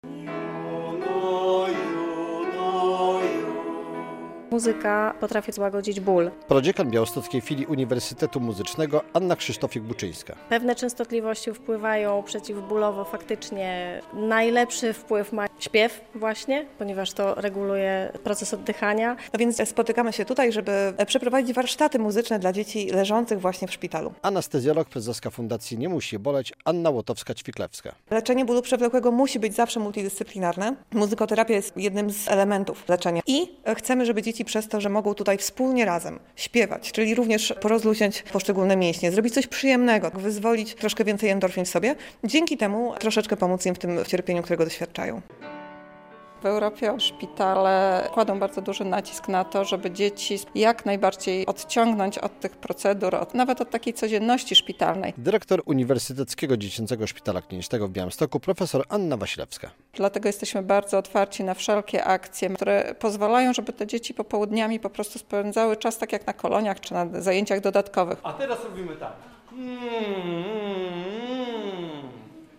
Muzykoterapia w UDSK - relacja